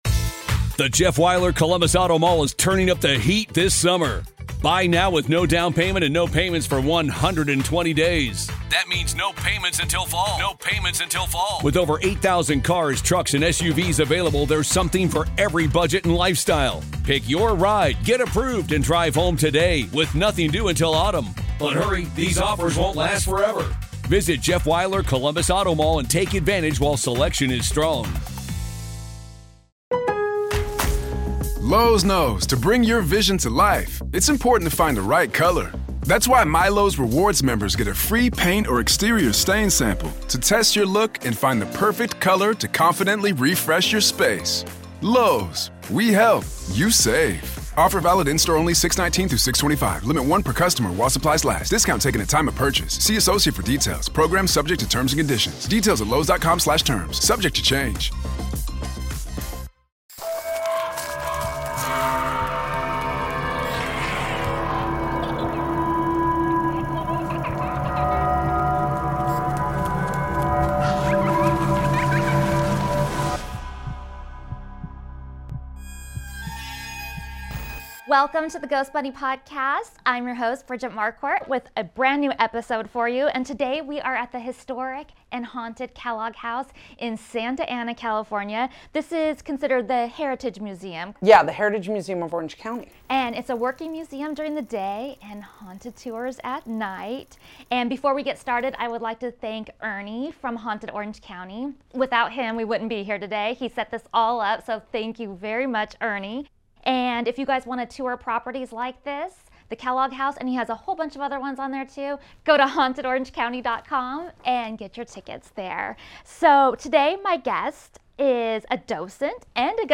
Listen closely for the spirits of the house making themselves known!